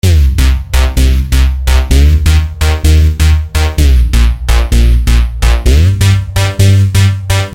描述：电子风格的贝司 128 BPM